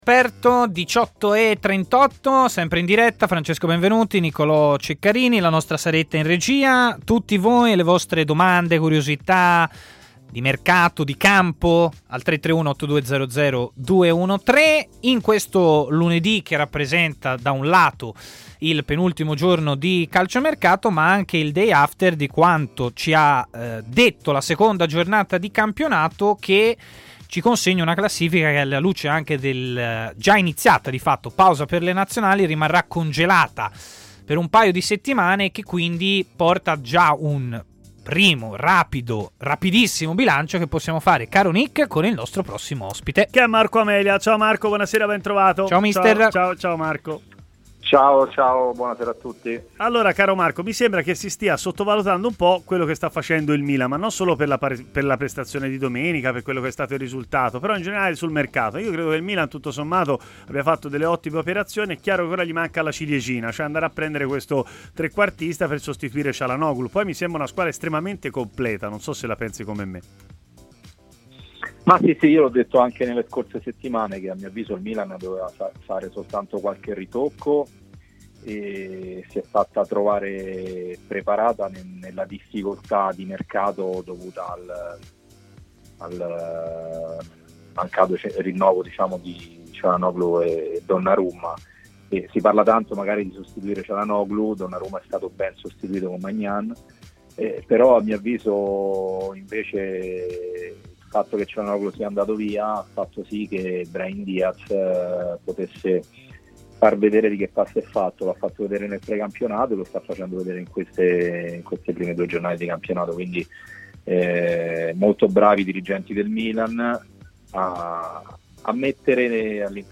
L'ex portiere Marco Amelia, oggi allenatore, ha così parlato a Stadio Aperto, trasmissione di TMW Radio